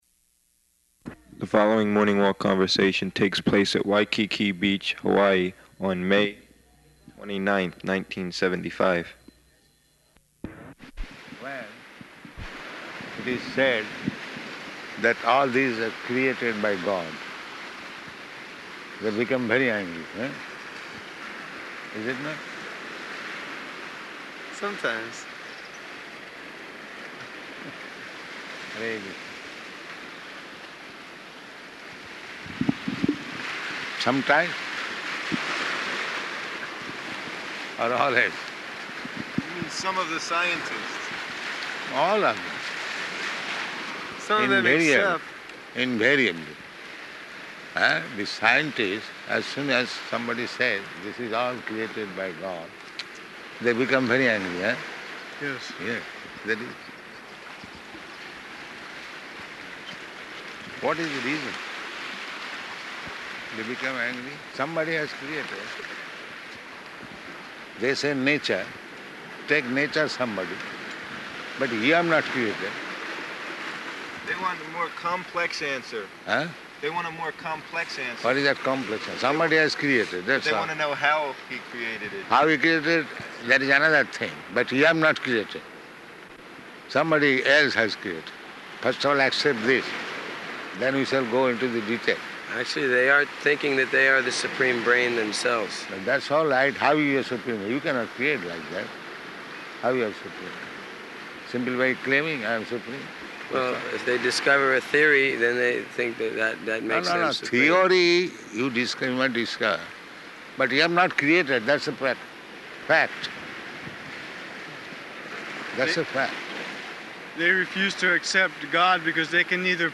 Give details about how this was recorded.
Morning Walk on Waikiki Beach